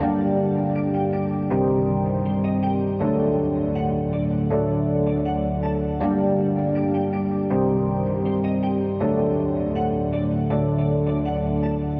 寒冷的钢琴嘻哈
描述：寒冷的钢琴在规模小的嘻哈乐器中。
Tag: 80 bpm Hip Hop Loops Piano Loops 2.02 MB wav Key : G